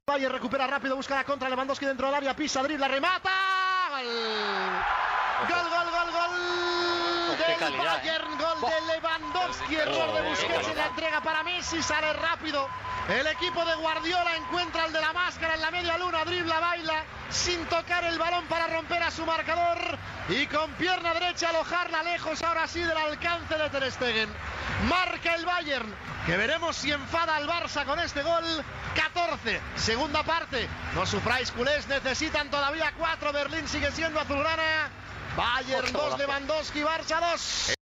Transmissió del partit de tornada de la fase eliminatòria de la Copa d'Europa de futbol masculí entre el Bayern München i el Futbol Club Barcelona.
Narració del segon gol del Bayern München, marcat per Robert Lewandowski.
Esportiu